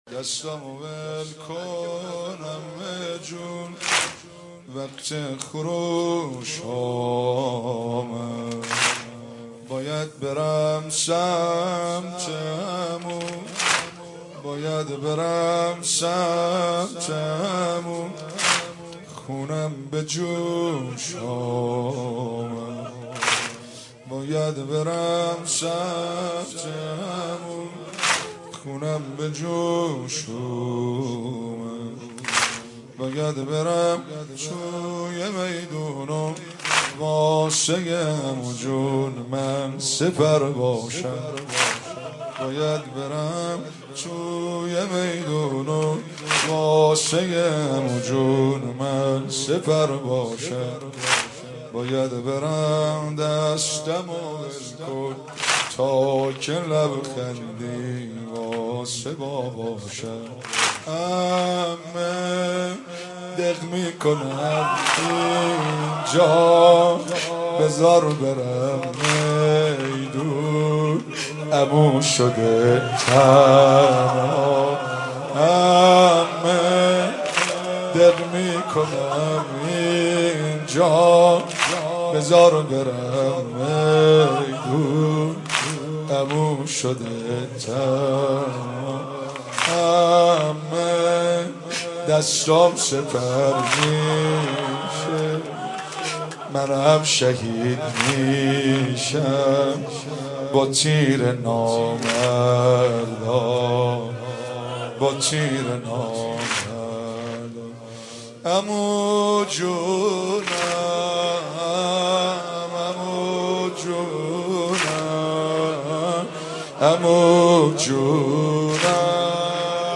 مداحی
نوحه